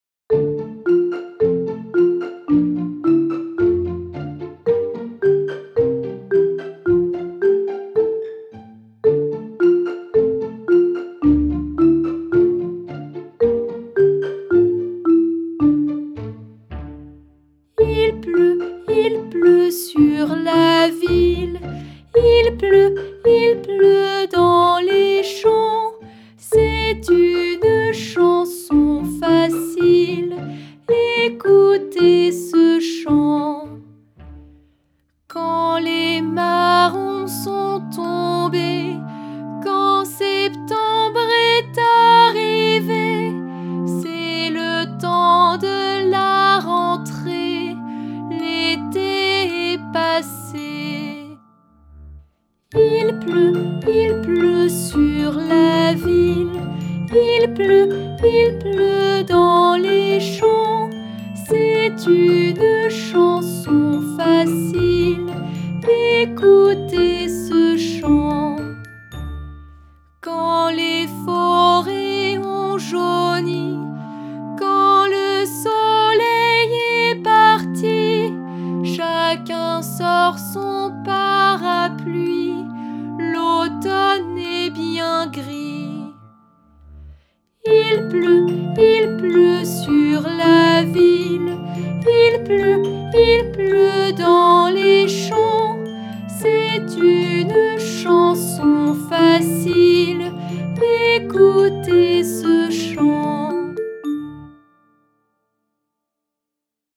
Genre :  ChansonComptine
Style :  A cappella
Effectif :  UnissonVoix égales
Enregistrement instrumental et voix